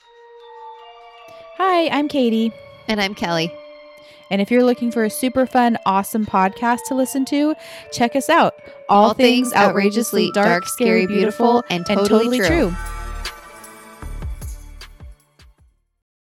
The one consistent aspect you'll be treated to is the hilarious banter between your hosts and the unique way these two bring the most complex ideas to life with an understandable, entertaining, and enjoyable delivery.